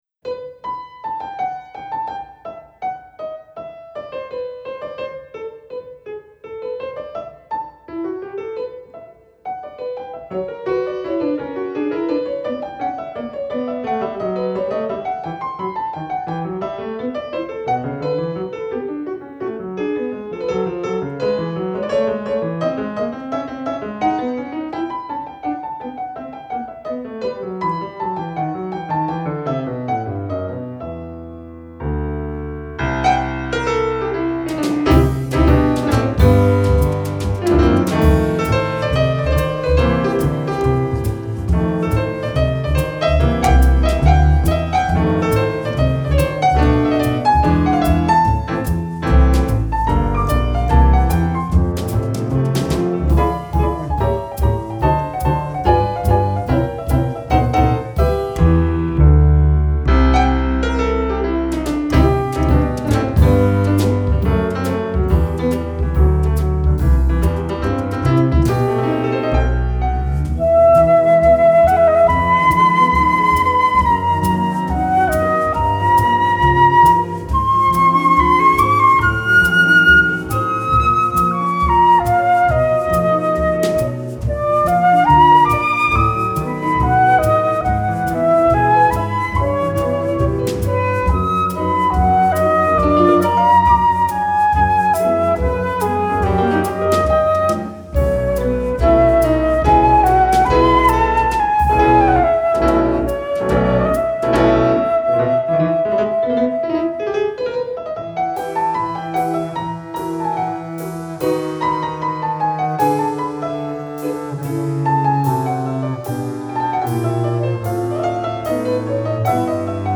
pianoforte
contrabbasso
batteria
GenereJazz